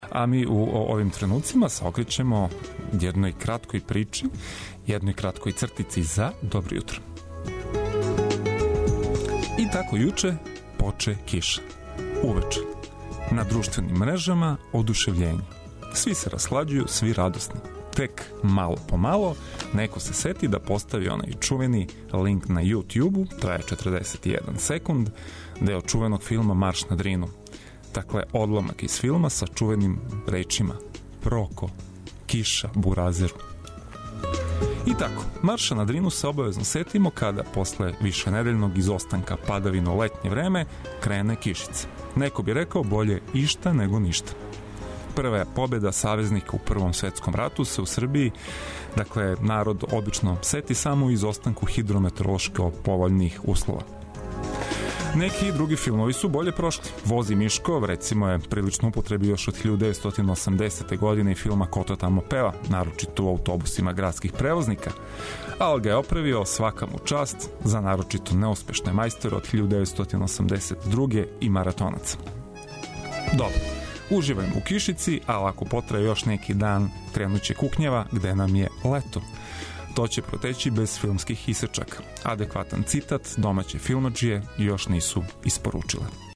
Будимо се заједно уз добру музику и преглед најбитнијих информација.